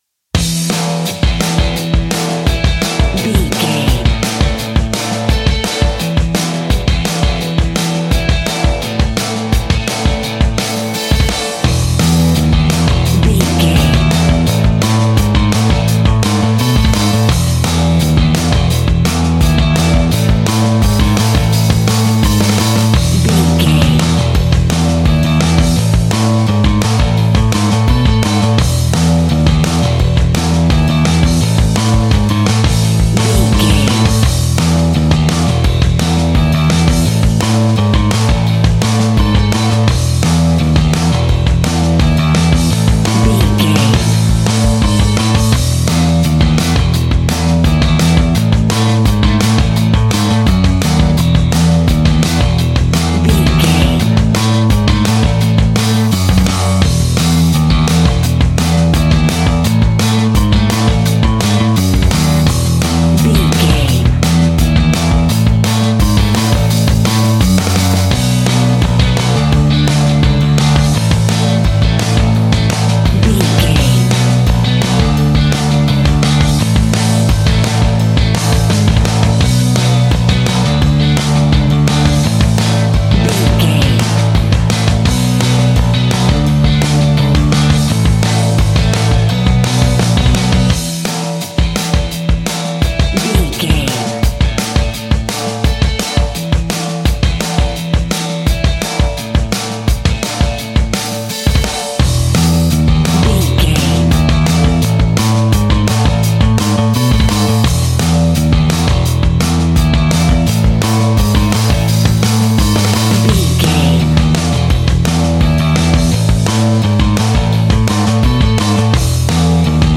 Mixolydian
fun
energetic
uplifting
cheesy
instrumentals
upbeat
rocking
groovy
guitars
bass
drums
piano
organ